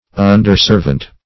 Underservant \Un"der*serv`ant\, n. An inferior servant.